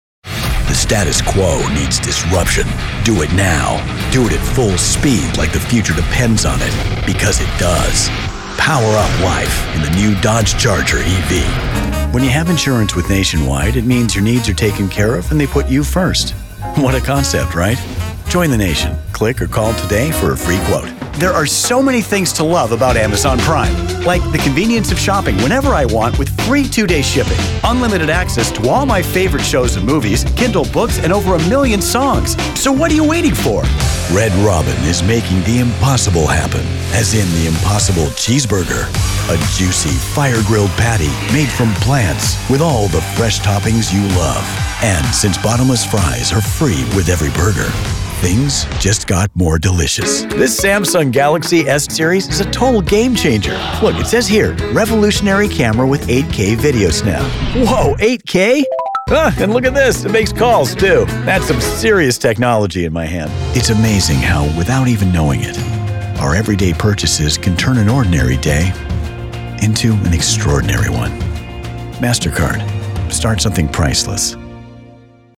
Commercials
Middle Aged
Microphone: Sennheiser MKH416
Acoustically treated home recording space